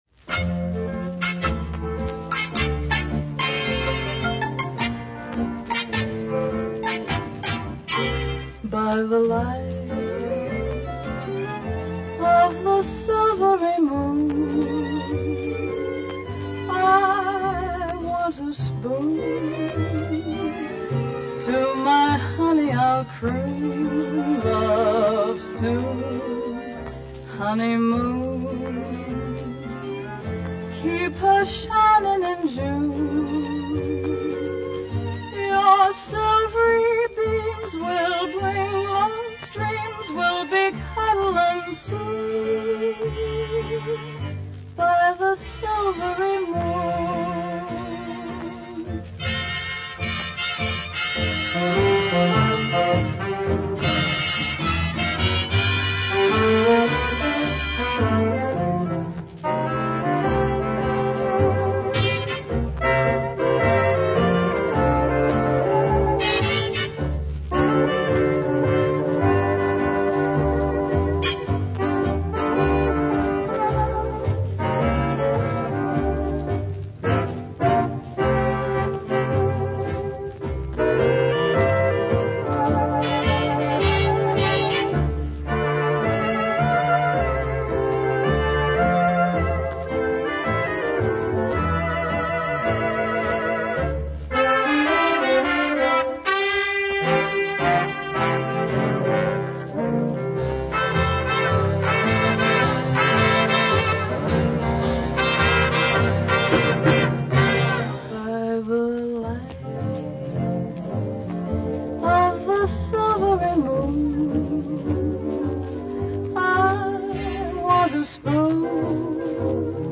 Sample sound files from the radio program: